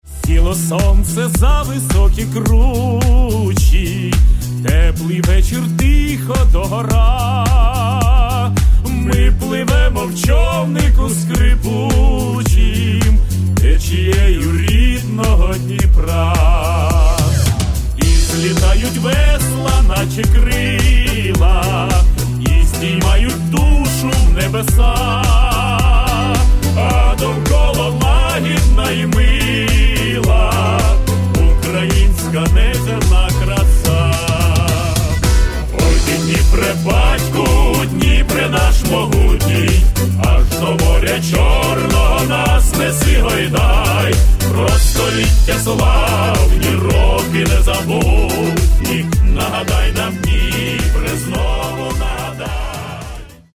Каталог -> Эстрада -> Группы